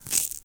grass9.ogg